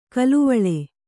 ♪ kaluvaḷe